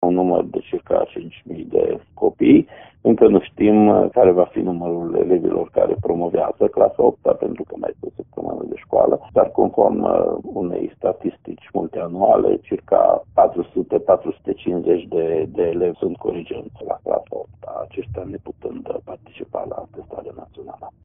În judeţul Mureş aproximativ 5.000 de elevi vor finaliza ciclul gimnazial, însă numărul lor se va fixa abia în ultima zi de şcoală, a explicat inspectorul şcolar general Ştefan Someşan.